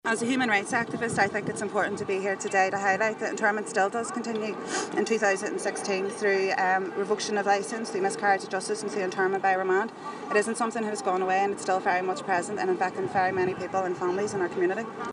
There's been a brief standoff between police and hundreds of marchers and band members at an anti-internment march in Belfast.